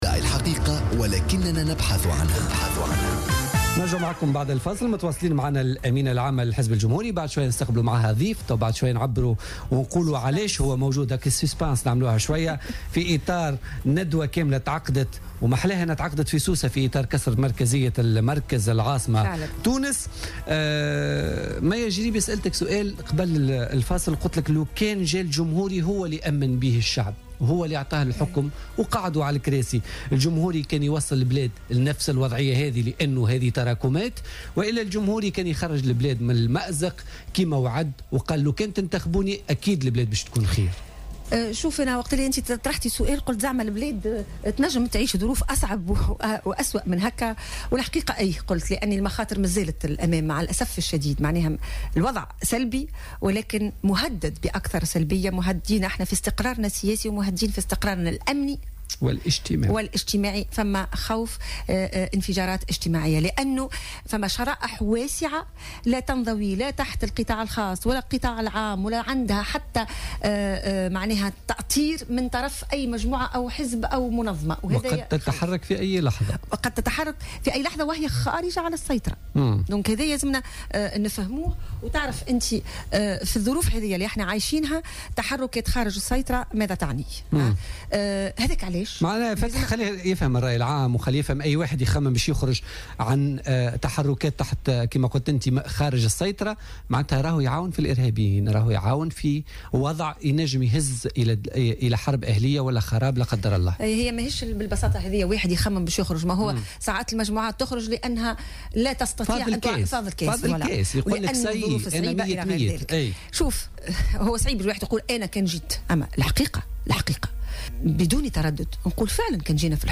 أكدت الأمينة العامة للحزب الجمهوري مية الجريبي ضيفة بوليتيكا اليوم الإثنين 18 جانفي 2016 أن المخاطر مازالت تحدق بتونس وأن الوضع سلبي ومهدد بأكثر سلبية مؤكدة أن التونسيون مهددون في استقرارهم الاقتصادي والسياسي والأمني والإجتماعي.